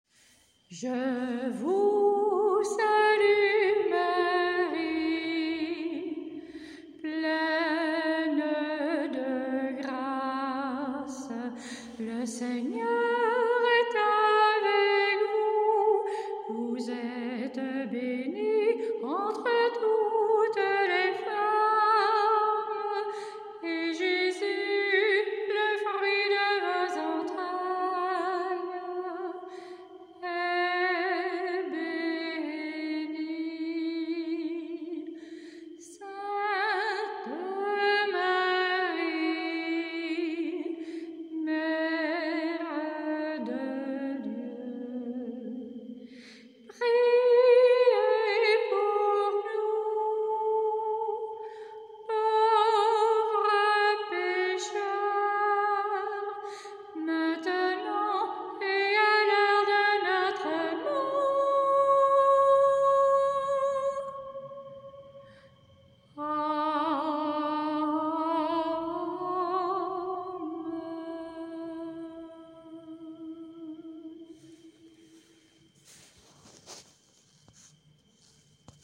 choriste à Bonlez vous propose un Ave Maria enregistré en l’église Sainte-Catherine de Bonlez.